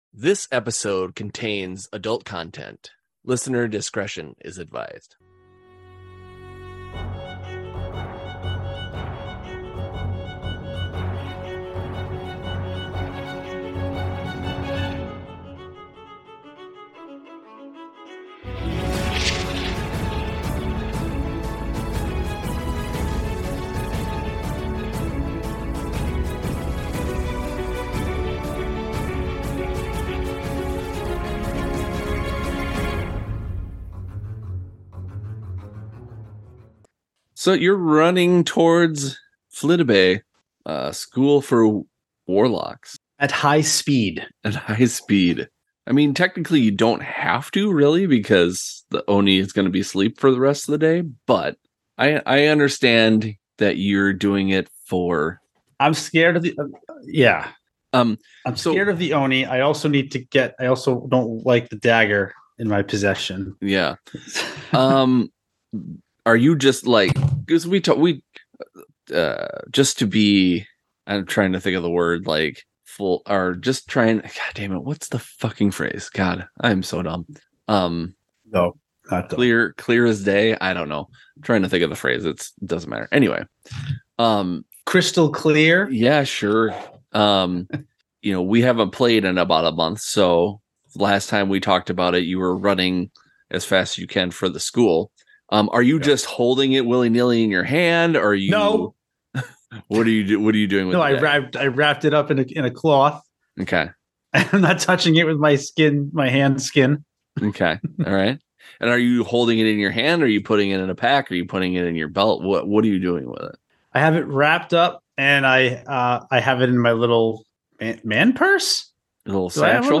Actual Play